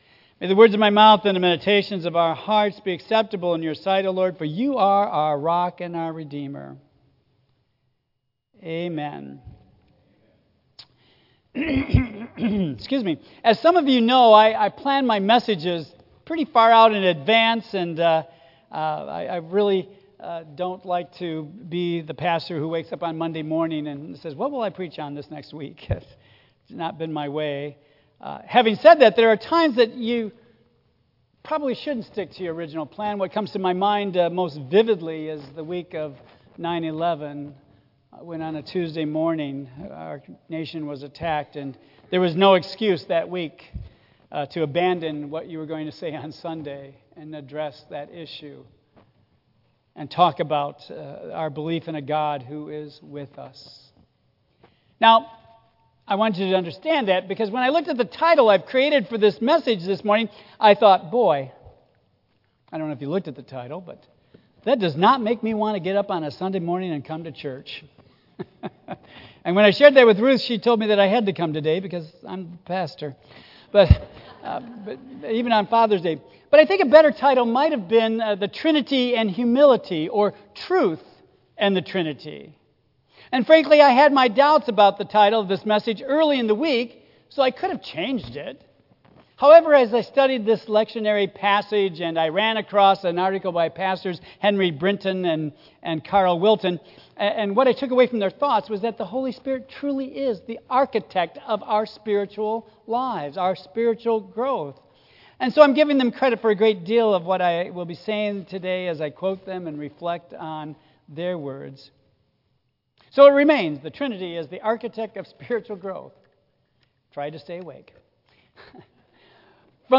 Trinity Sunday and Father’s Day Message While it’s Father’s Day, it is also Trinity Sunday when we celebrate the mystery of the Holy Trinity.
Tagged with Michigan , Sermon , Waterford Central United Methodist Church , Worship